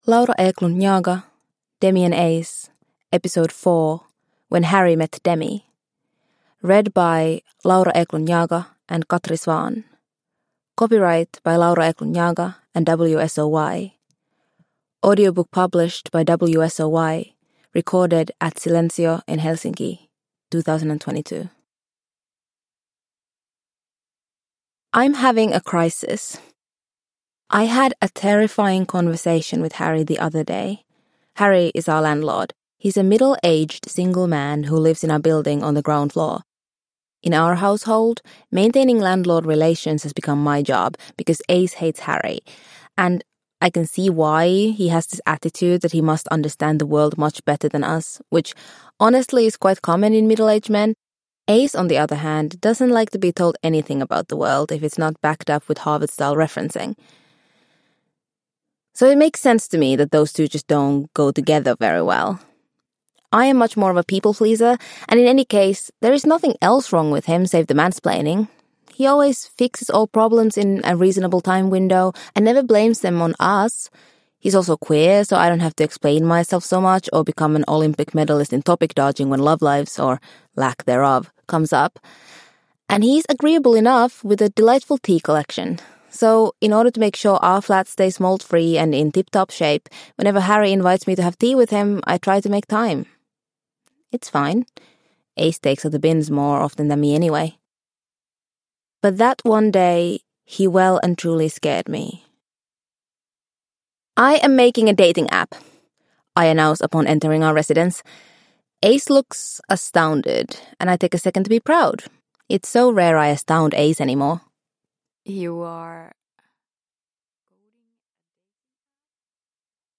Demi and Ace 4: When Harry Met Demi – Ljudbok – Laddas ner